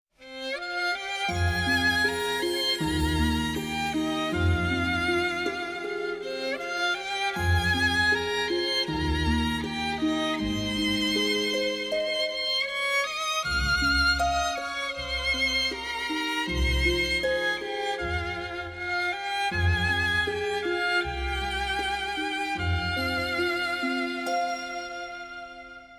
классические